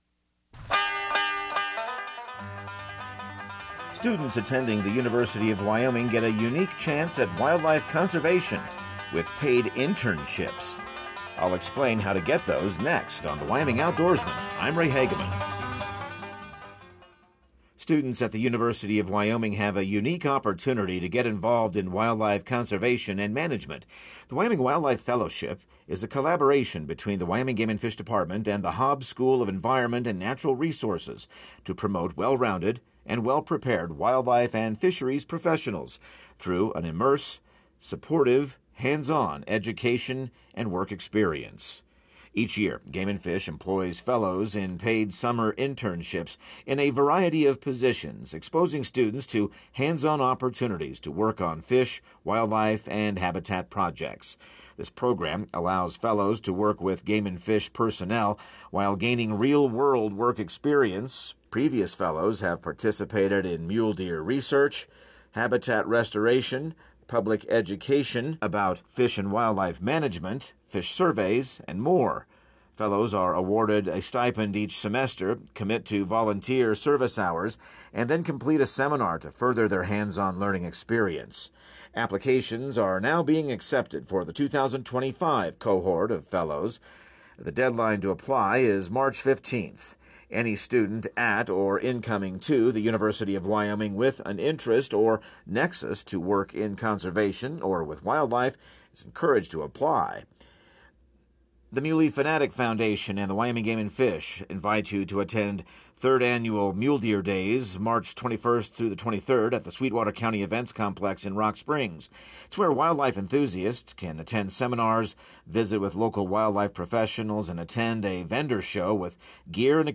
Radio news | Week of December 9